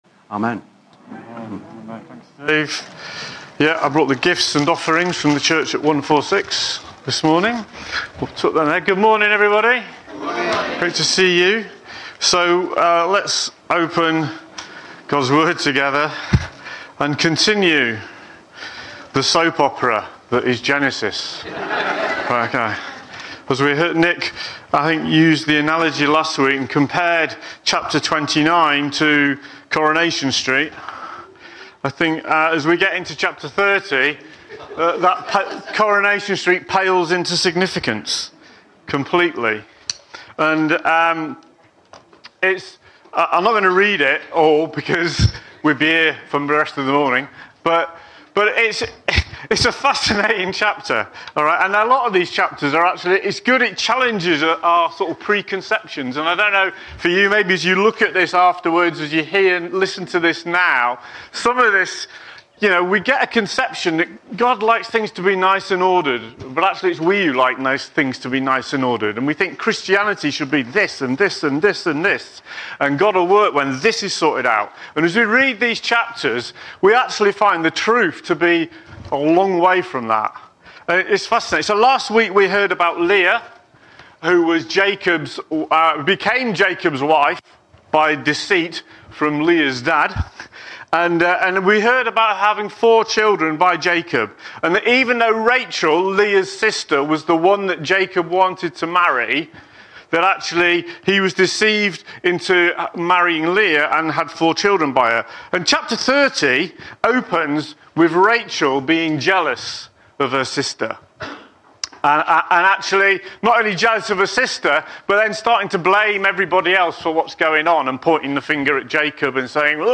Sermon Title: Relationships – Genesis 30
The sermon is delivered in a conversational and relatable style, making it accessible to a wide audience.